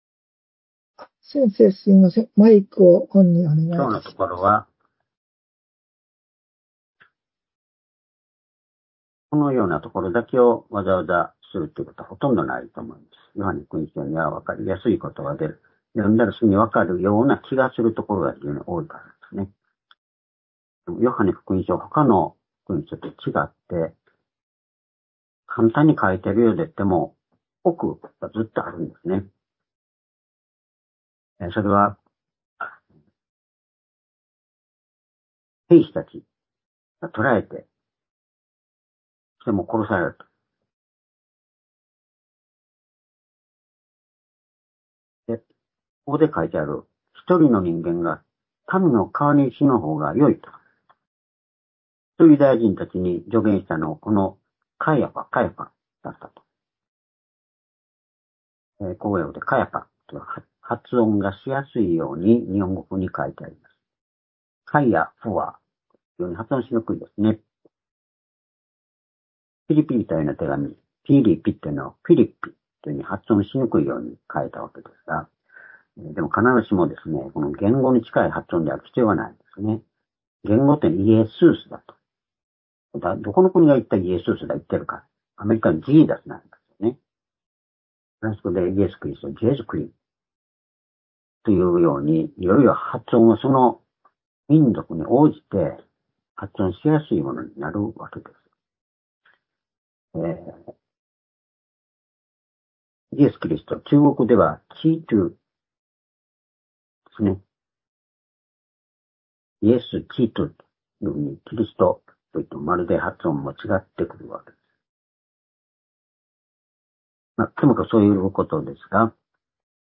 主日礼拝日時 ２０２４年１０月１３日（主日礼拝） 聖書講話箇所 「一人の人間が、民の代わりに死ぬ」 ヨハネ１８の１２～１４ ※視聴できない場合は をクリックしてください。